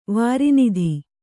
♪ vāri nidhi